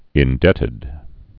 (ĭn-dĕtĭd)